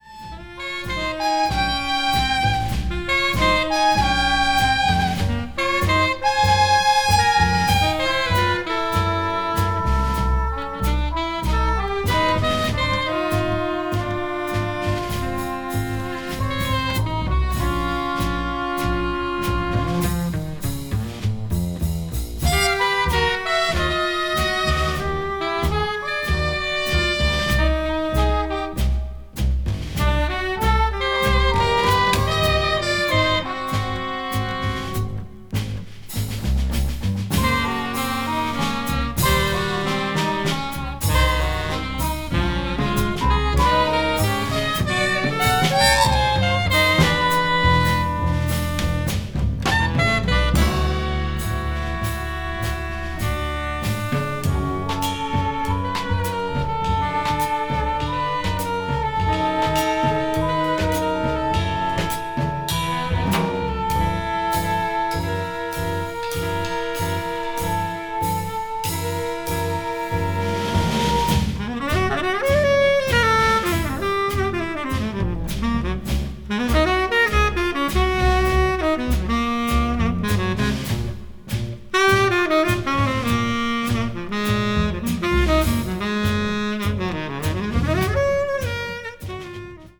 avant-jazz   contemporary jazz   ethnic jazz   free jazz